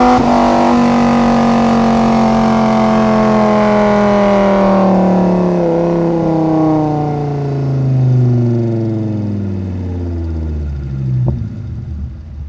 v10_02_decel.wav